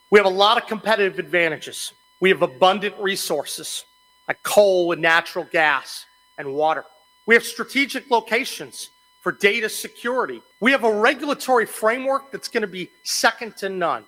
West Virginia Governor Patrick Morrisey has signed the Power Generation and Consumption Act into law.  He signed the bill in Point Pleasant where a 2,000 acre data center campus is slated for construction, a type of project that Morrisey says the new law is designed to attract.